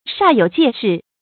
煞有介事 shà yǒu jiè shì 成语解释 真像有那么一回事似的。
成语简拼 syjs 成语注音 ㄕㄚˋ ㄧㄡˇ ㄐㄧㄝ ˋ ㄕㄧˋ 常用程度 常用成语 感情色彩 中性成语 成语用法 动宾式；作谓语、定语、状语；含贬义 成语结构 动宾式成语 产生年代 古代成语 成语正音 煞，不能读作“shā”。